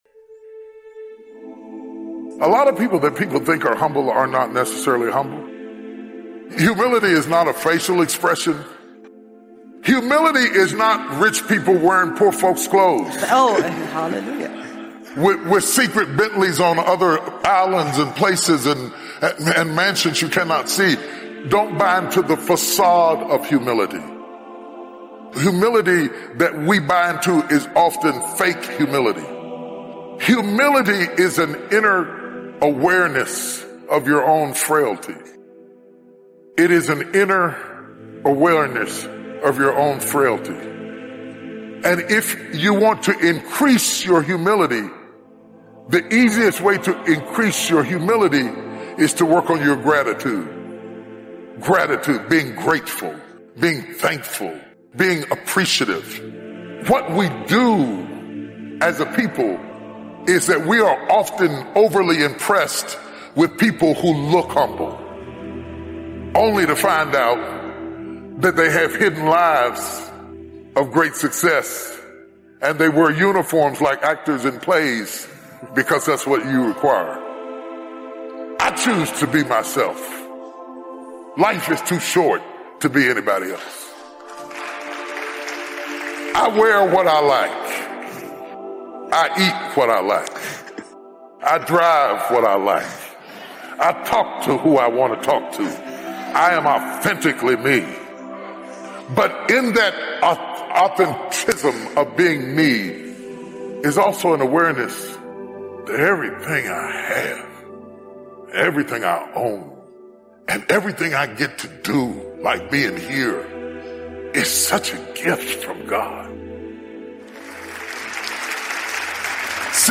Speakers: T.D Jakes